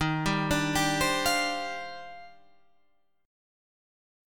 EbM13 chord